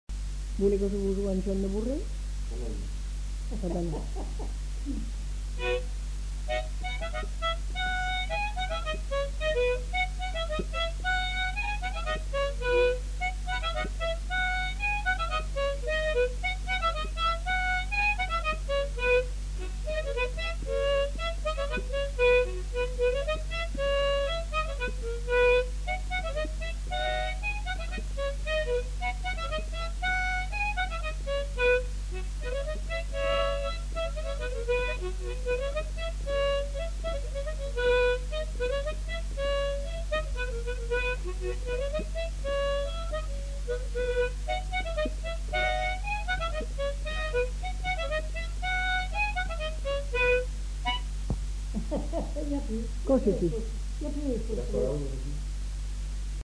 Aire culturelle : Haut-Agenais
Genre : chant
Effectif : 1
Type de voix : voix de femme
Production du son : chanté
Danse : bourrée